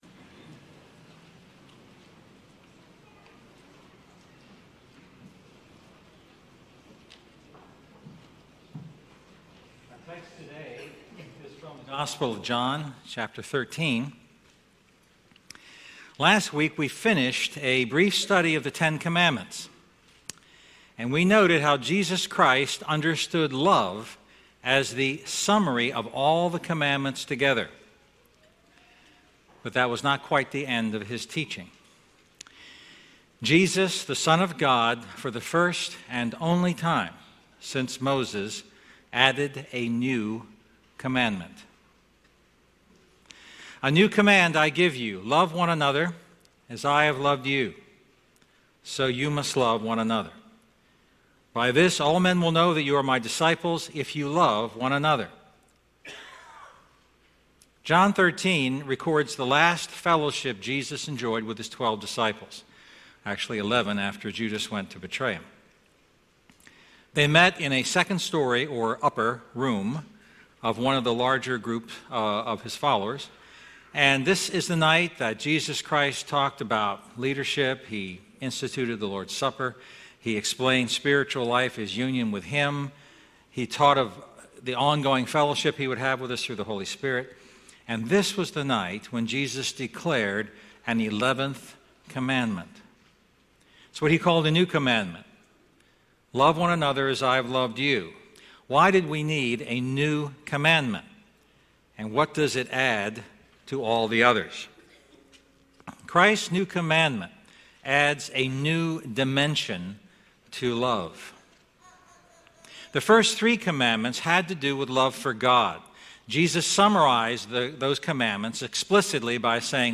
A message from the series "Love Unpacked."